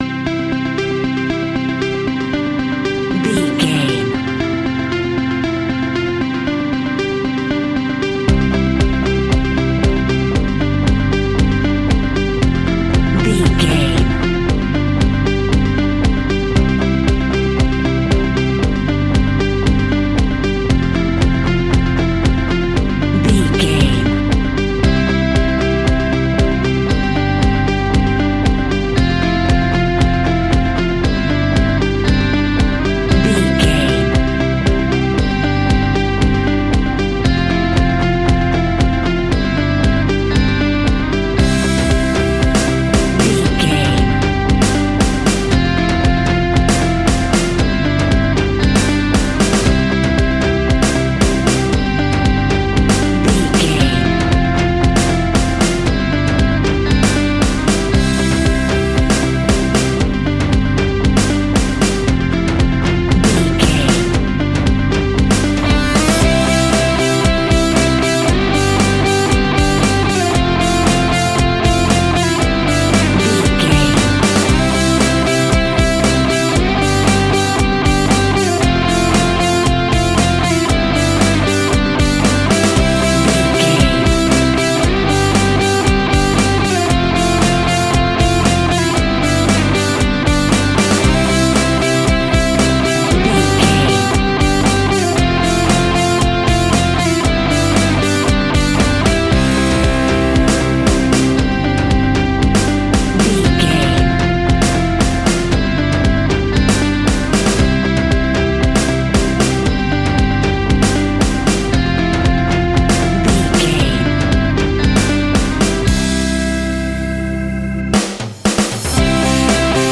Ionian/Major
pop rock
indie pop
energetic
uplifting
upbeat
groovy
guitars
bass
drums
piano
organ